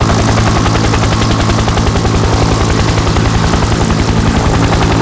1 channel
CHINOOK.mp3